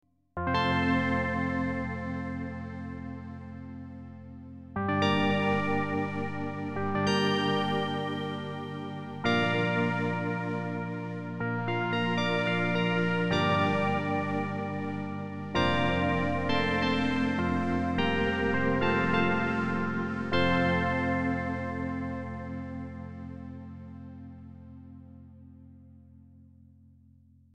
Sachant que les sons que tu nous as fait écouter sont traité avec des effets style reverbe etc.
Mais il s'agit bel et bien de sons typiquement FM.
Rien que des sons FM très classiques et tout à fait reproductibles sur un DX7.